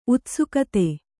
♪ utsukate